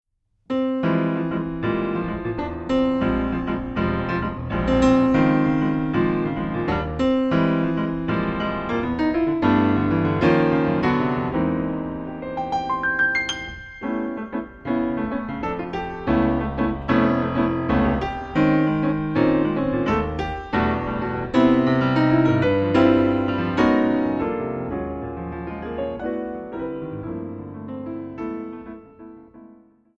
Solo Piano Concert
Recording: Ralston Hall, Santa Barbara, CA, January, 2008
Piano